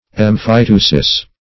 Emphyteusis \Em`phy*teu"sis\, n. [L., fr. Gr.
emphyteusis.mp3